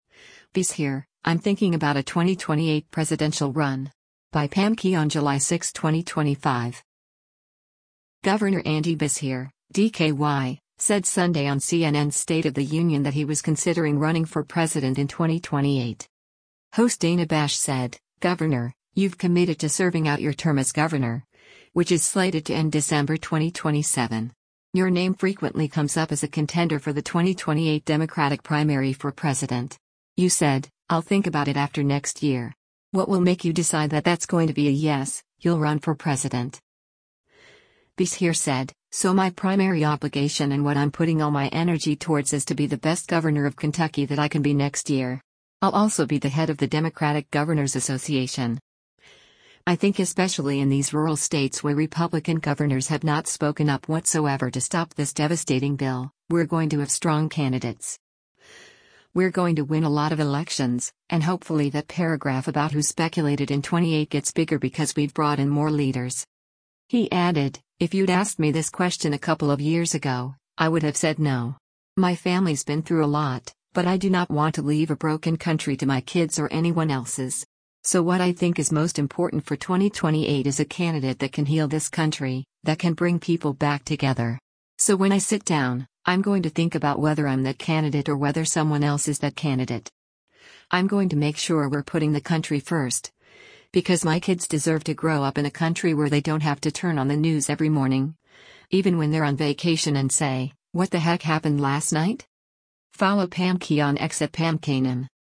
Governor Andy Beshear (D-KY) said Sunday on CNN’s “State of the Union” that he was considering running for president in 2028.